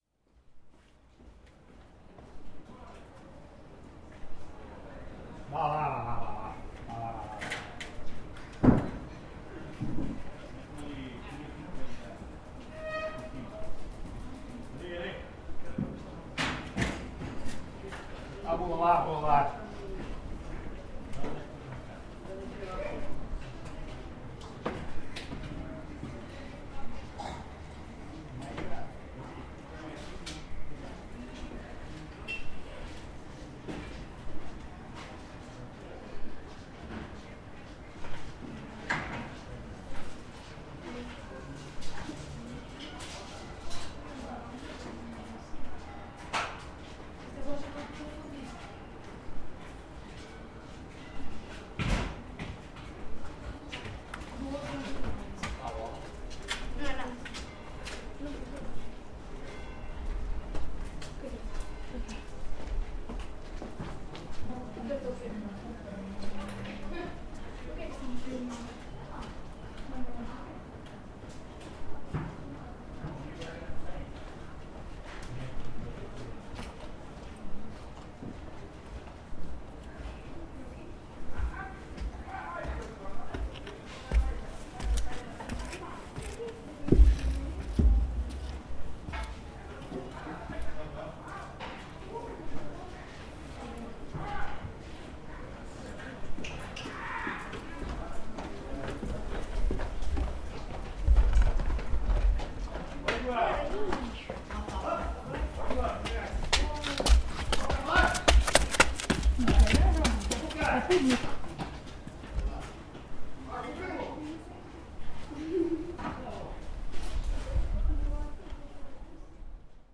Gravação do som de uma das muitas obras de reabilitação de edifícios existentes no centro histórico de Viseu. Outros sons de transeuntes acabam por preencher a gravação. Gravado Zoom H4.
NODAR.00114 – Viseu: Rua Direita – Empregados de uma obra falam e transeuntes passam
Viseu-Rua-Direita-Empregados-de-uma-obra-falam-e-transeuntes-passam.mp3